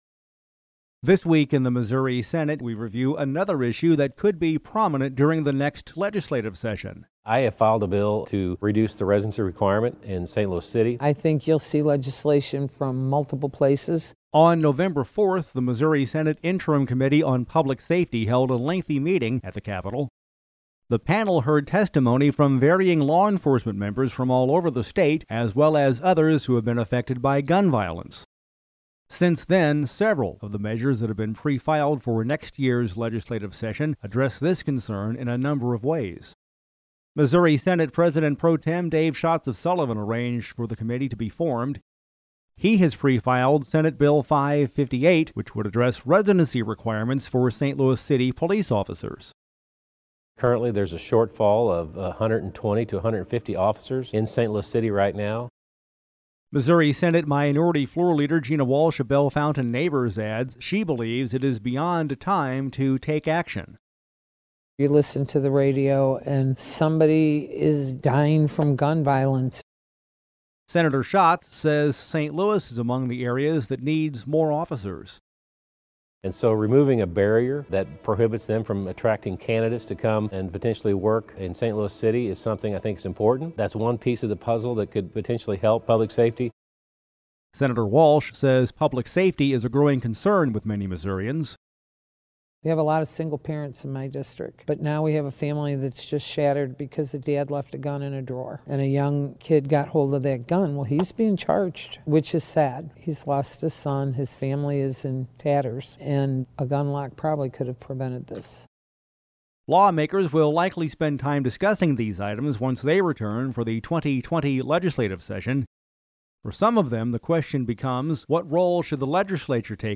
Dec. 27: This Week in the Missouri Senate reviews another of the issues that could be a discussion point during the next legislative session, which will start on Jan. 8. We’ve included actualities from Missouri Senate President Pro Tem Dave Schatz, R-Sullivan, and Missouri Senate Minority Floor Leader Gina Walsh, D-Bellefontaine Neighbors, in this feature report